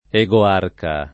egoarca [ e g o # rka ] s. m.; pl. ‑chi